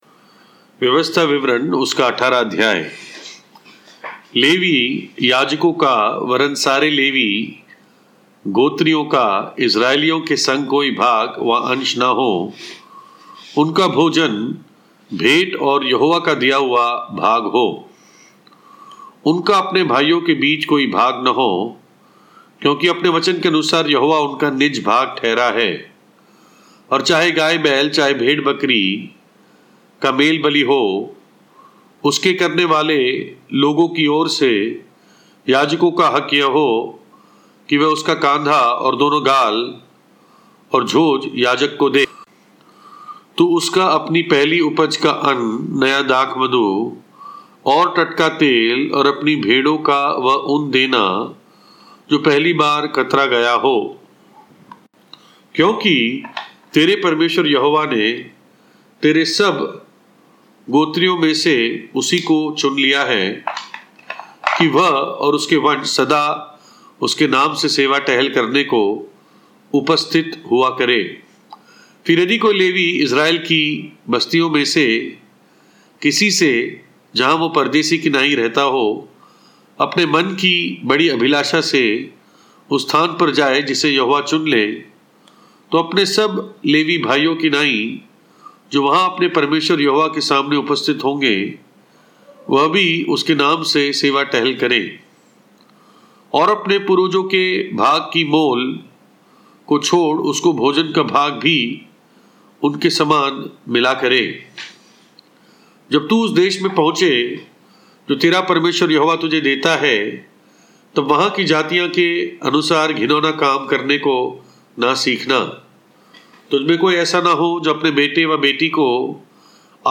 Deuteronomy- व्यवस्थाविवरण- Hindi Audio Bible - Borivali Assembly